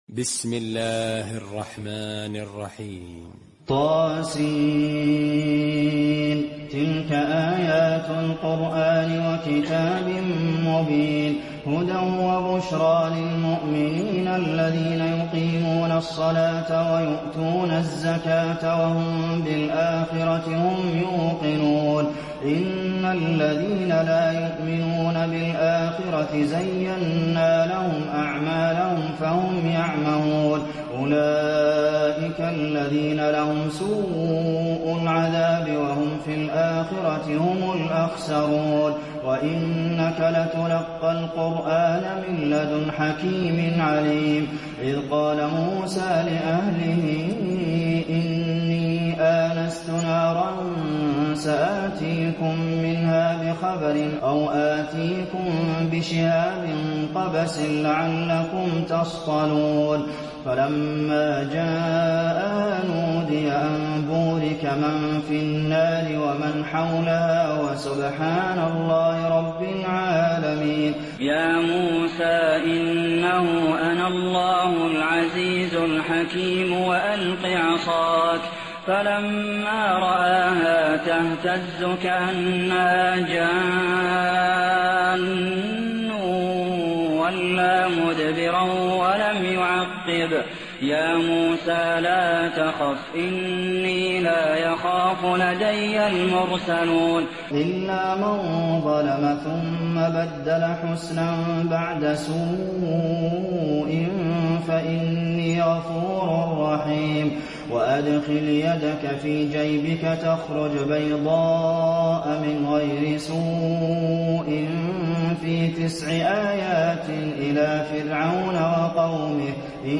المكان: المسجد النبوي النمل